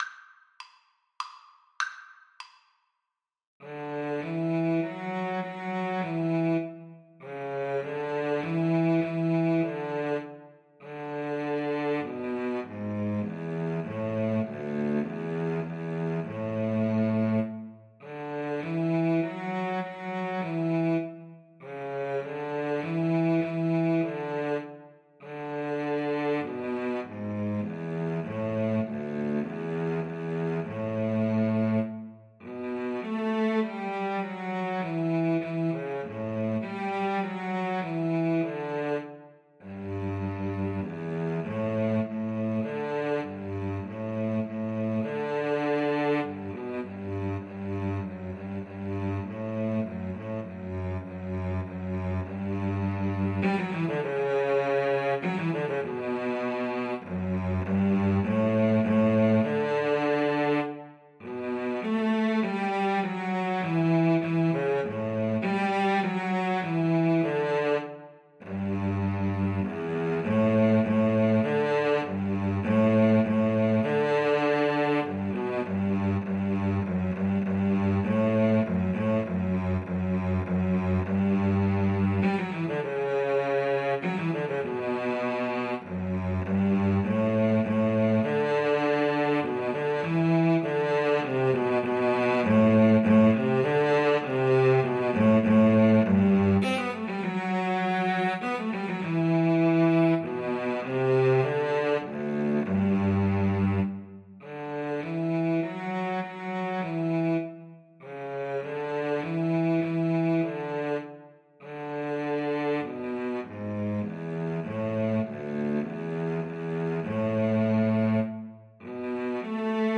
Free Sheet music for Cello Duet
Cello 1Cello 2
D major (Sounding Pitch) (View more D major Music for Cello Duet )
3/4 (View more 3/4 Music)
Classical (View more Classical Cello Duet Music)